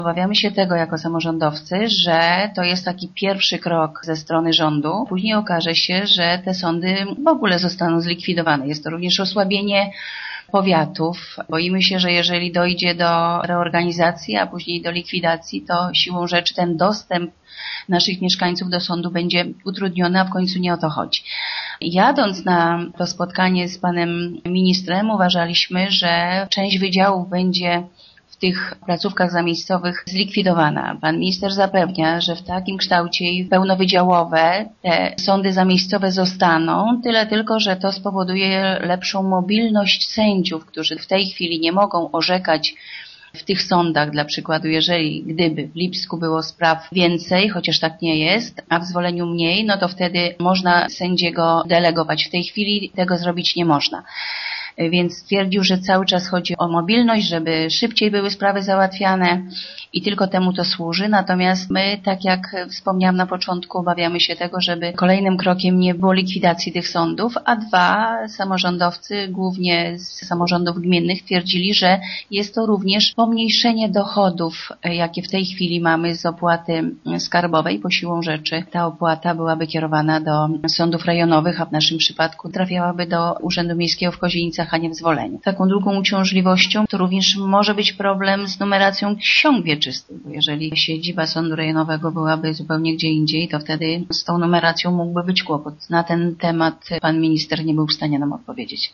Może się też okazać, że zapowiadane zmiany nie będą ostatnimi – mówi burmistrz Zwolenia Bogusława Jaworska: